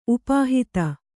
♪ upāhita